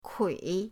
kui3.mp3